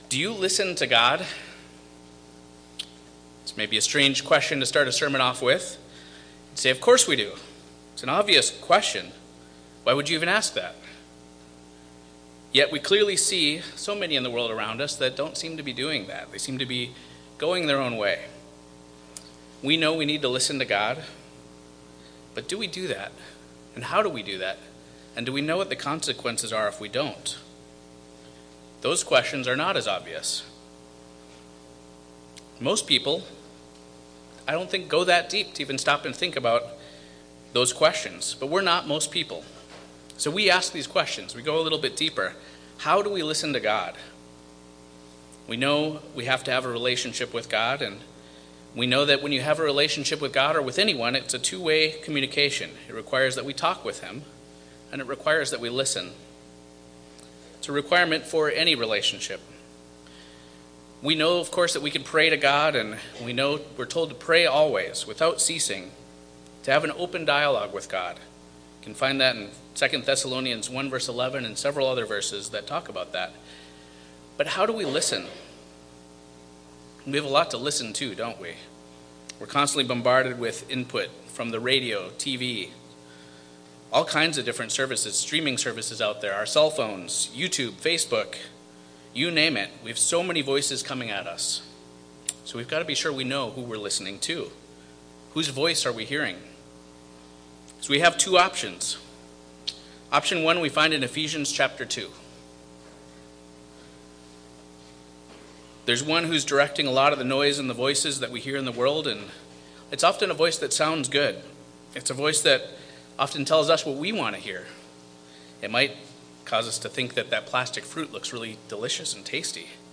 Sermons
Given in Eau Claire, WI